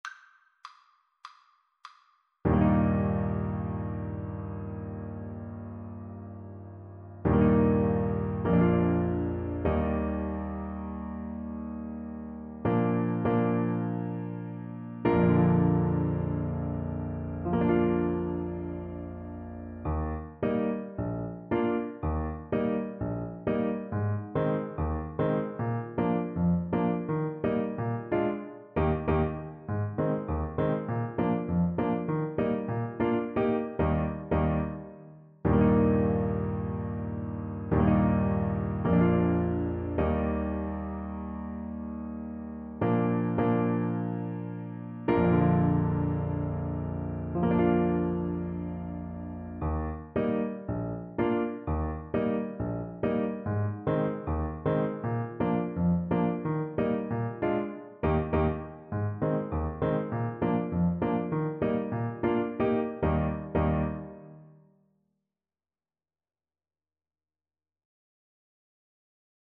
Play (or use space bar on your keyboard) Pause Music Playalong - Piano Accompaniment Playalong Band Accompaniment not yet available transpose reset tempo print settings full screen
Alto Saxophone
4/4 (View more 4/4 Music)
G4-Bb5
Eb major (Sounding Pitch) C major (Alto Saxophone in Eb) (View more Eb major Music for Saxophone )
Moderately and freely